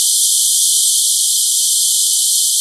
rr3-assets/files/.depot/audio/sfx/forced_induction/turbo_01.wav
turbo_01.wav